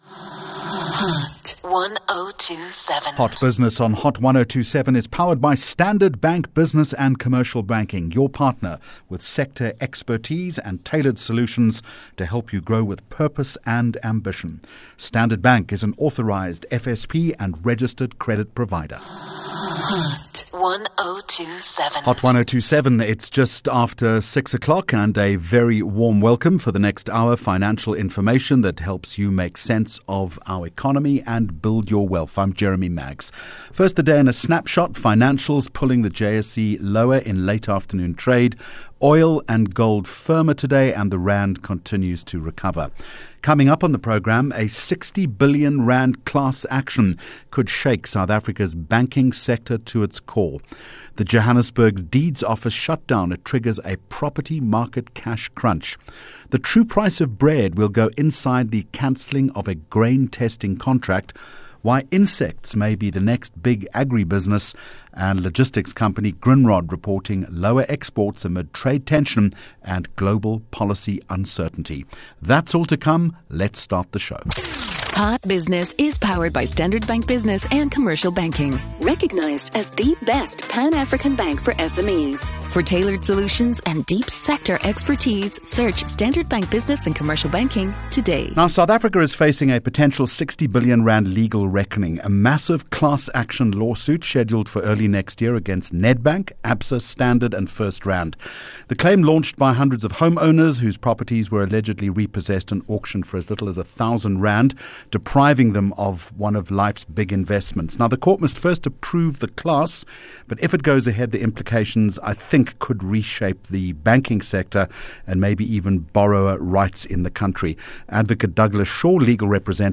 25 Jun Hot Business Interview